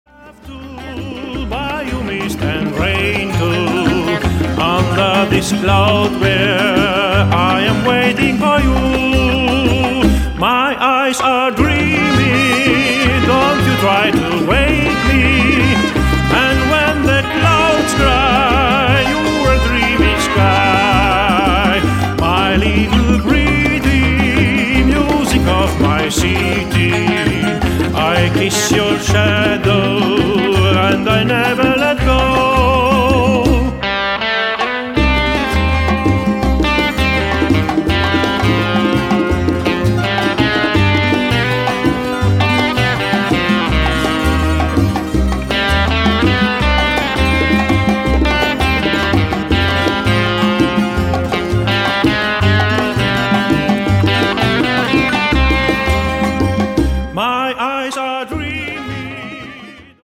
Grandioser Gesang